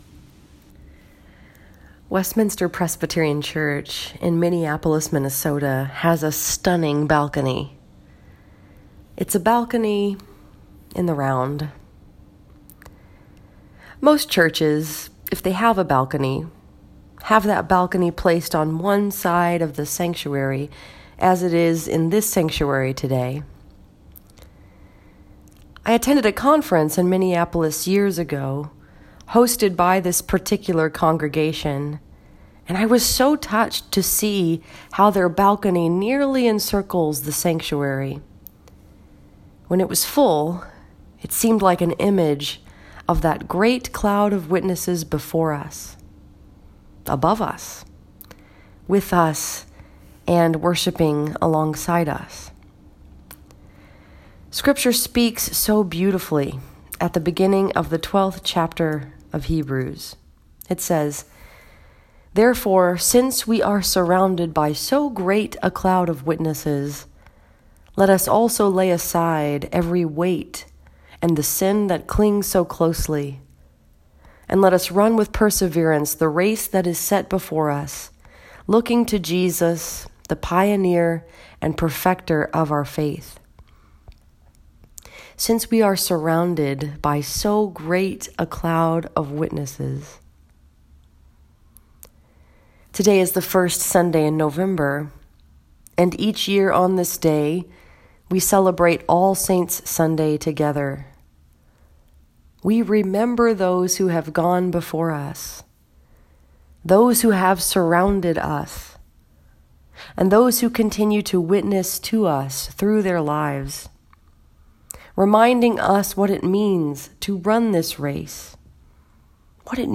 This sermon was preached at First Presbyterian Church in Howell, Michigan and was focused upon the story that is told in Hebrews 12:1-2.